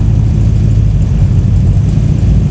cvpi_idle.wav